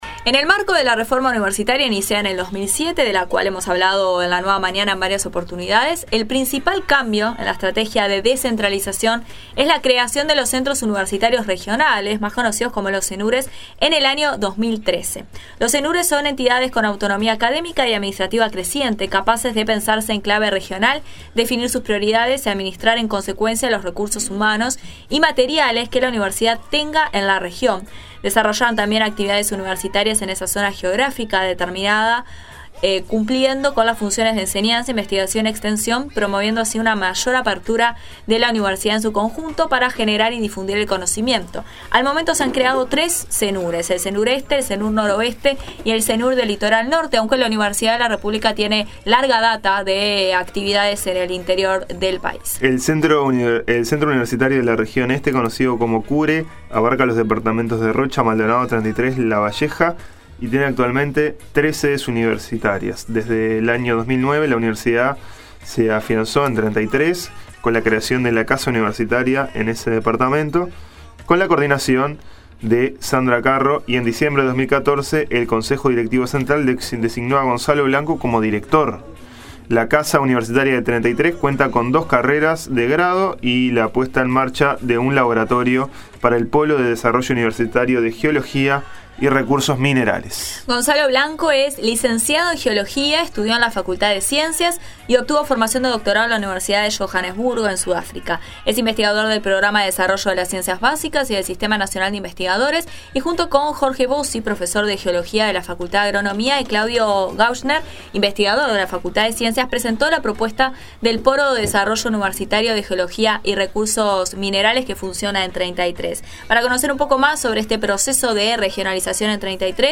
La Universidad se afianza en el Este, entrevista